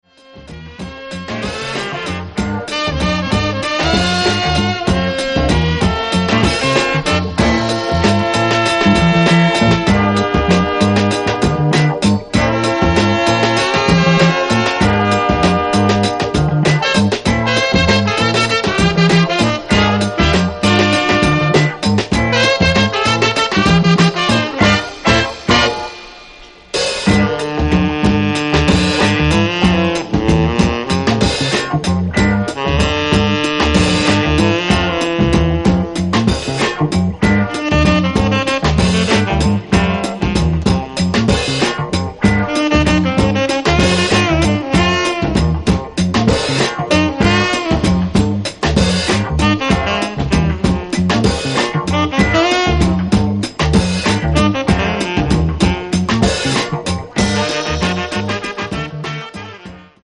Easily one of the best jazz/funk albums of this decade.